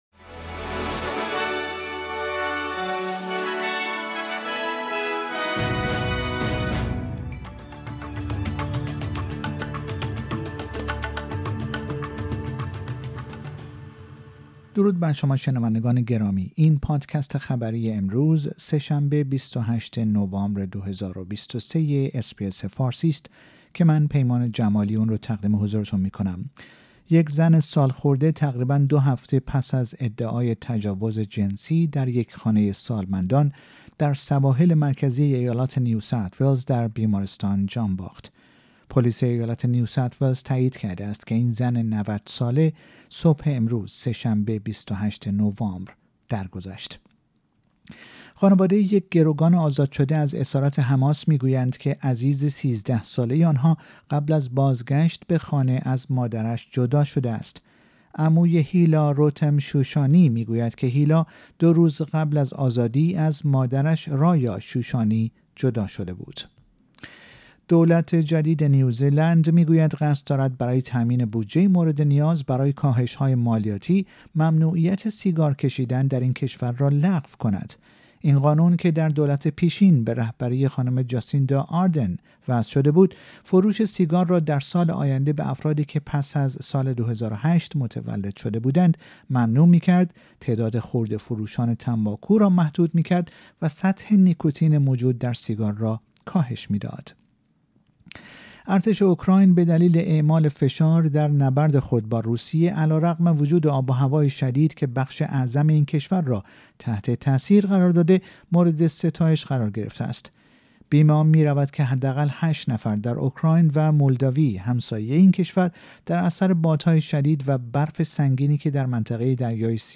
در این پادکست خبری مهمترین اخبار استرالیا و جهان در روز سه شنبه ۲۸ نوامبر ۲۰۲۳ ارائه شده است.